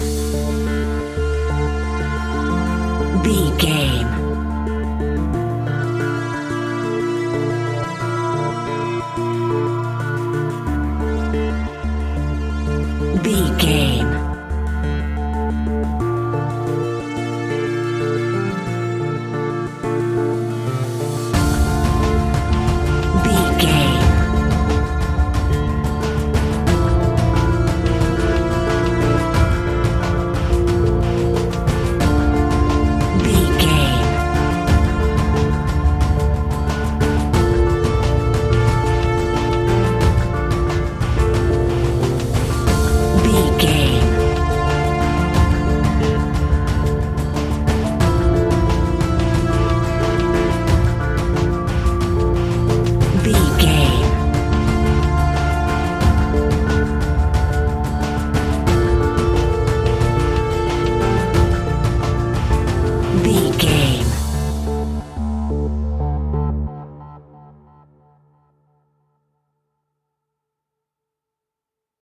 In-crescendo
Aeolian/Minor
D
scary
ominous
dark
haunting
eerie
synthesiser
tense
mysterious
electronic music
Horror Pads
Horror Synths